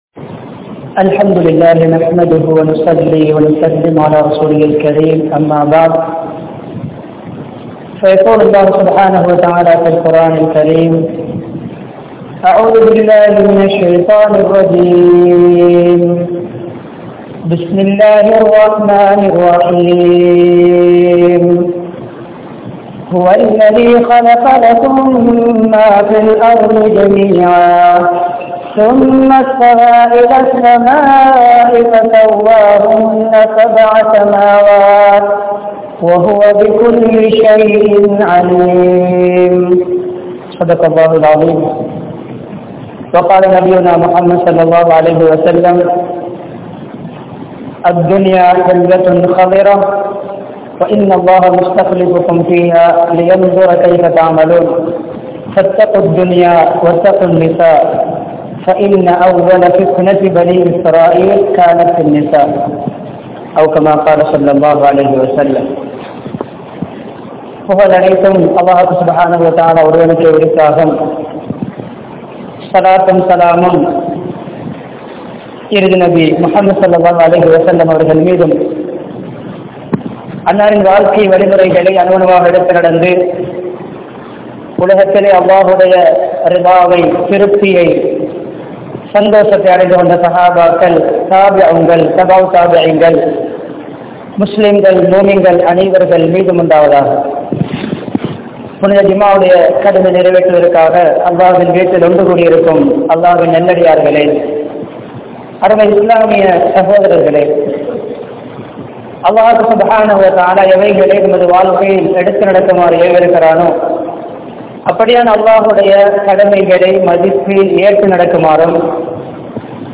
Mobile Phone in Vifareethangal (கைத்தொலைபேசியின் விபரீதங்கள்) | Audio Bayans | All Ceylon Muslim Youth Community | Addalaichenai